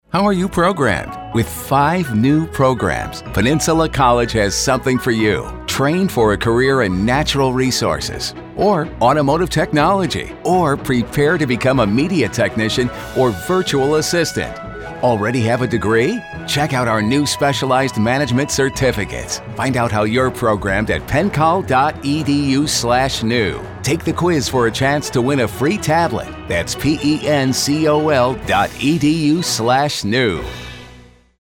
radio ad that focused on the new programs and quiz ran regularly on the local radio station.
Radio-Ad-June-2023.mp3